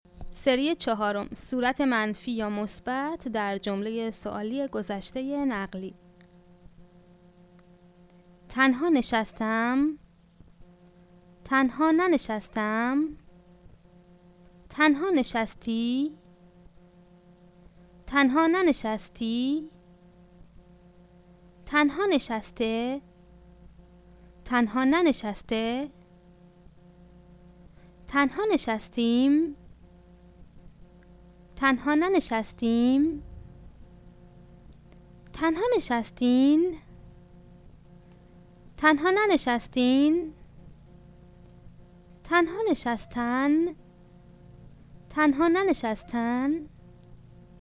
The Present Perfect is stressed on the last syllable and the Simple Past on the penultimate syllable.